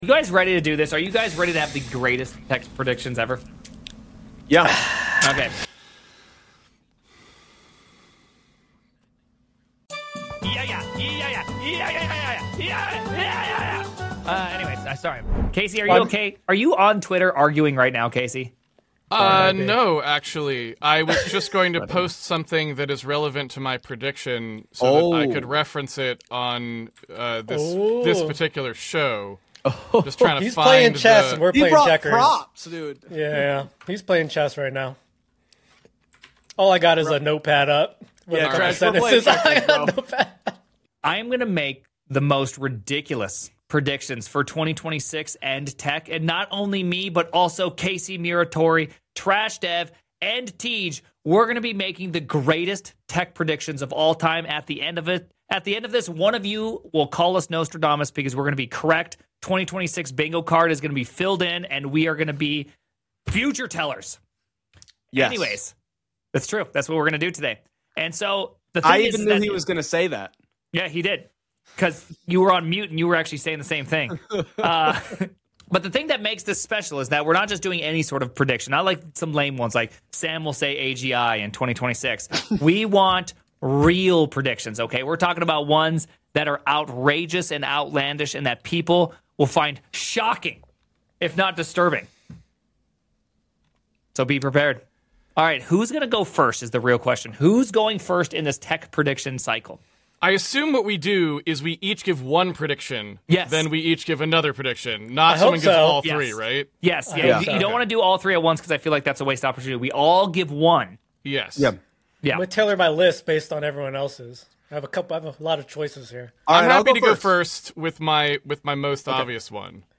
The panel also speculates on Amazon's potential acquisition of Anthropic and the rise of human-only spaces to protect authenticity in an AI-driven world. Buckle up for a flavorful mix of serious insights and playful banter! 00:00 forum Ask episode play_arrow Play